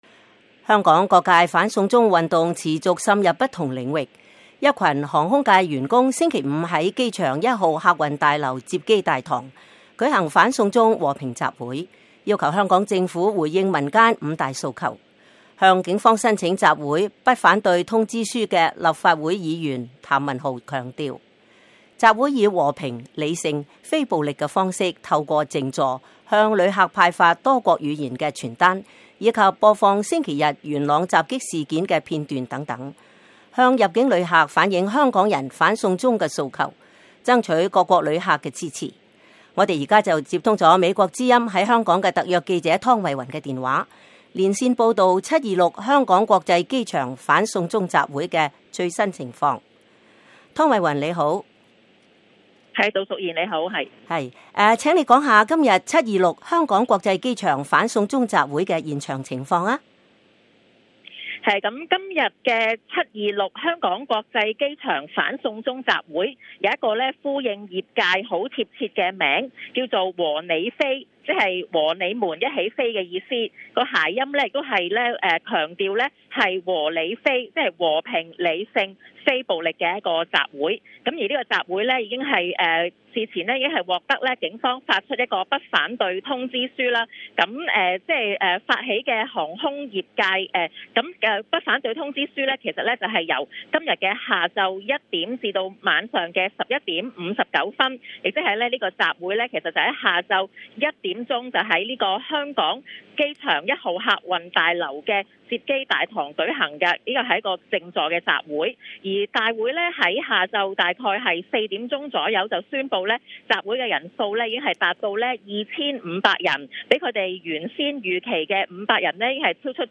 香港機場反送中集會現場報道